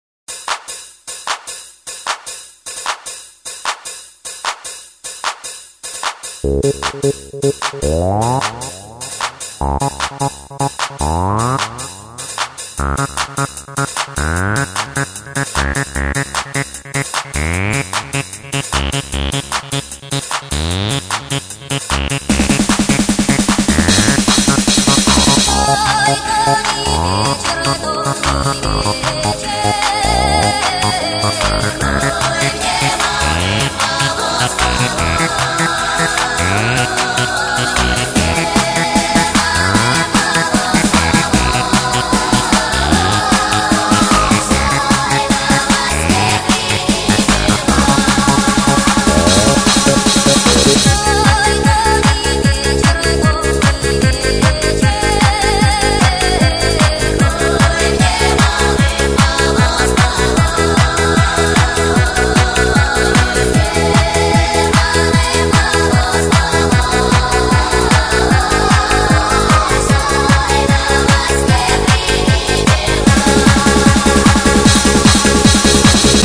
Очередная версия клубняка